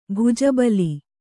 ♪ bhuja bali